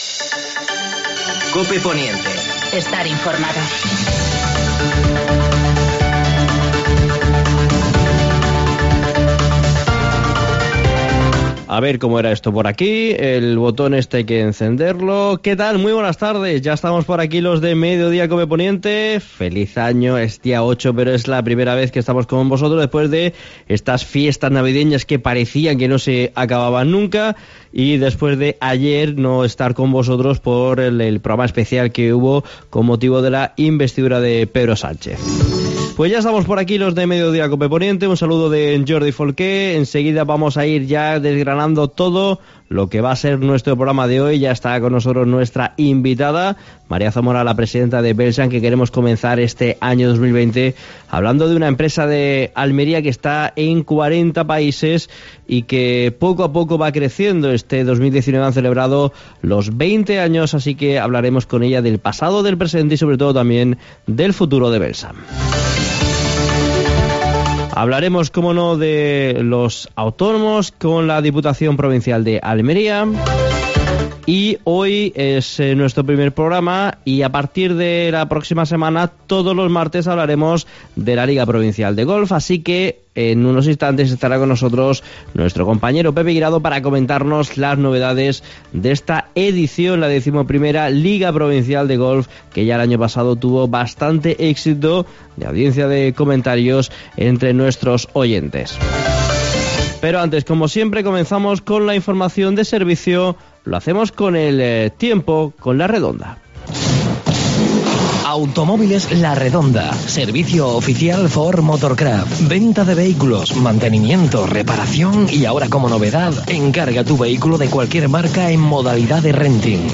Vuelta de Mediodía COPE Poniente (87.7 FM) tras las fechas navideñas y la investidura de Pedro Sánchez como presidente del Gobierno este martes, para contar lo más importante que ocurre en la Comarca del Poniente almeriense.